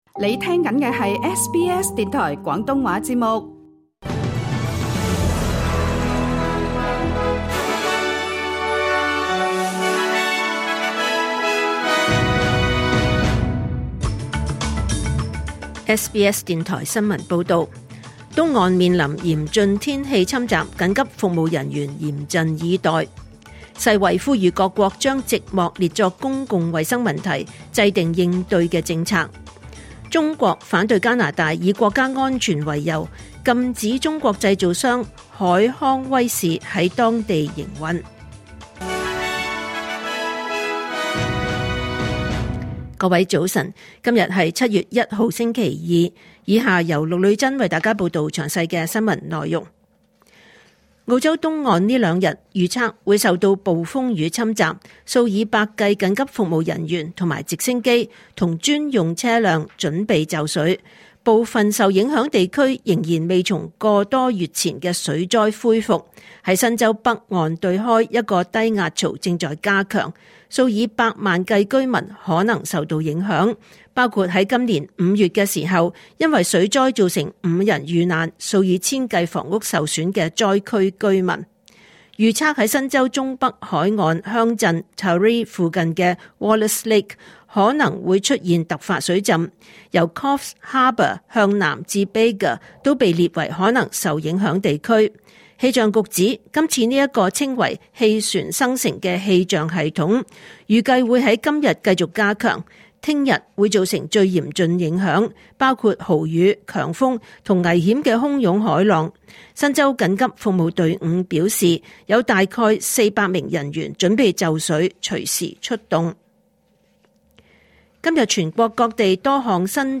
2025年7月1日SBS廣東話節目九點半新聞報道。